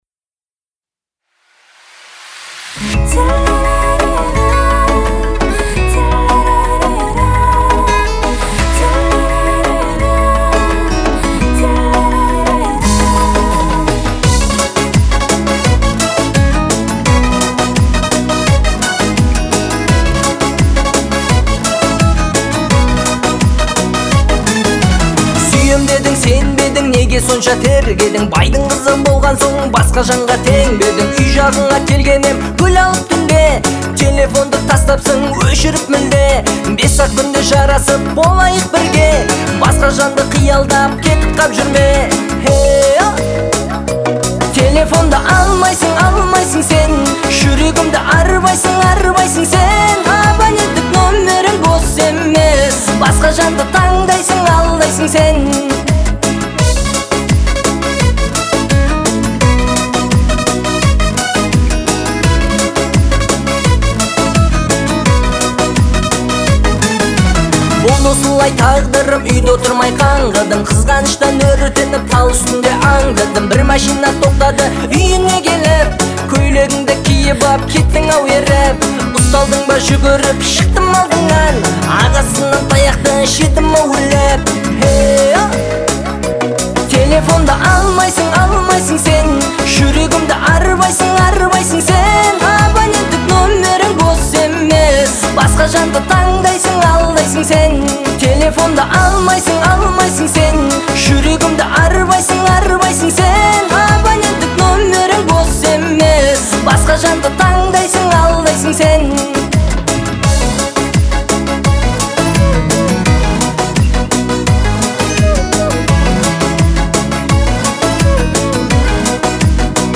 это яркая композиция в жанре поп